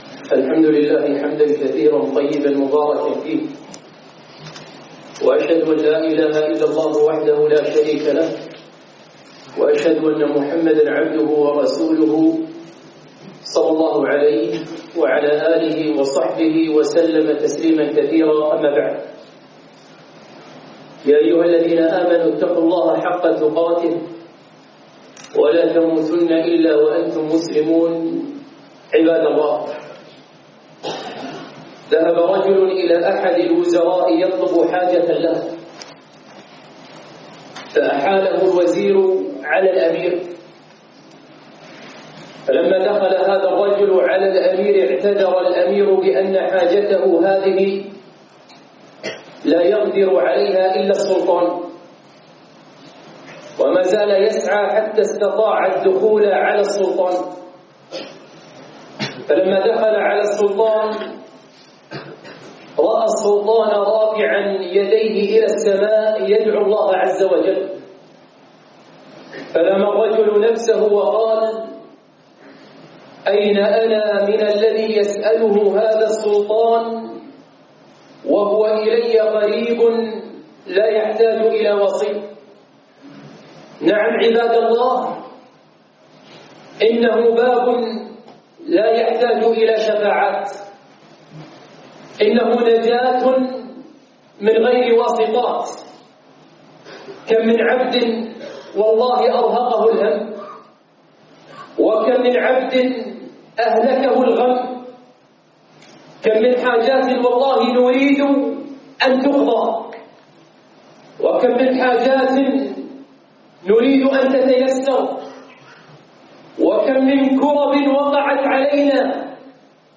خطبة - تقربوا إلى الله بالدعاء (ألقيت في فرنسا)